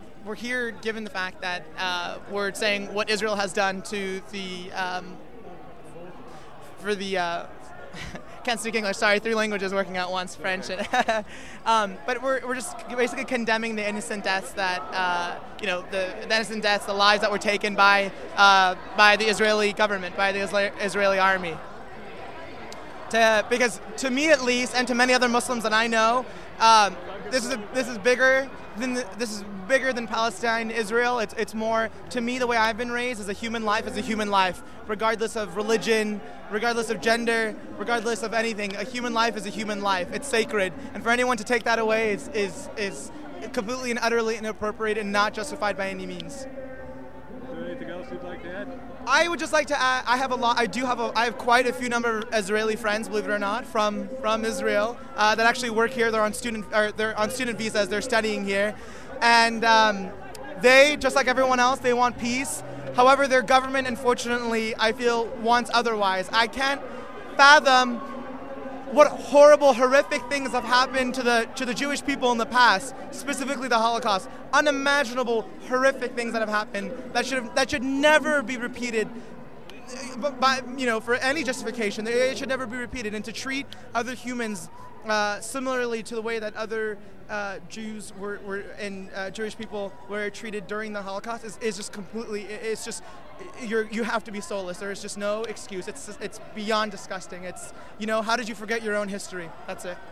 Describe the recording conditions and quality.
Two speech excerpts and three interviews from the 6/4/10 protest at the Israeli consulate